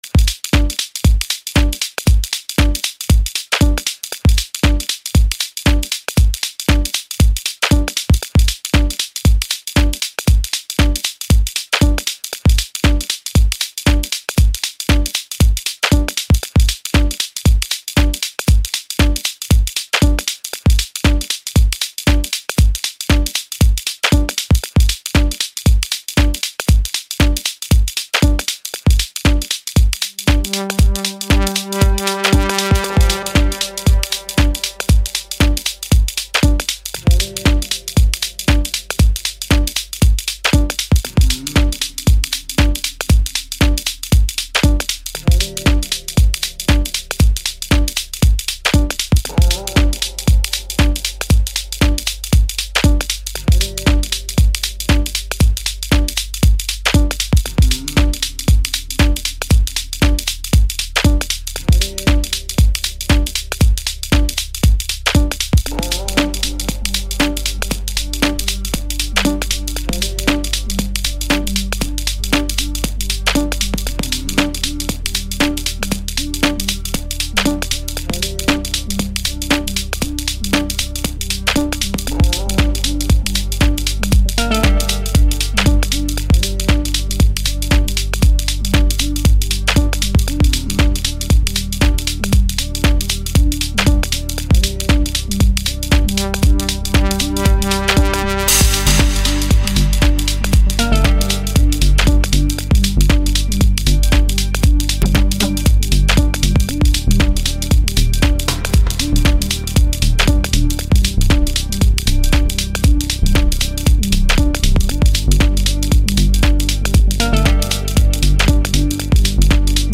Home » Amapiano » DJ Mix » Hip Hop
South African singer-songwriter